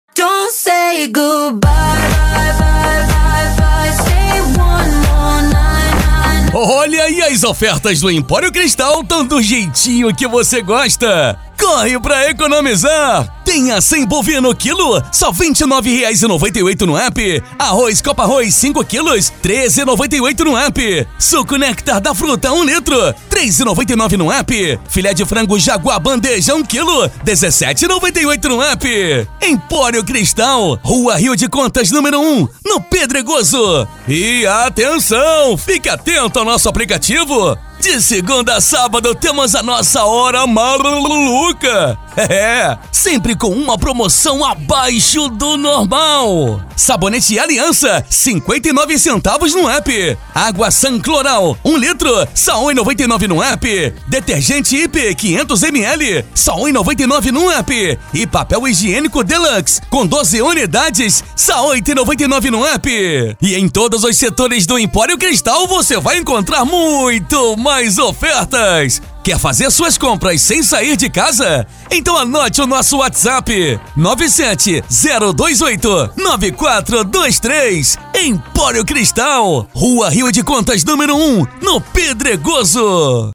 AUDIO COM PROMOÇÃO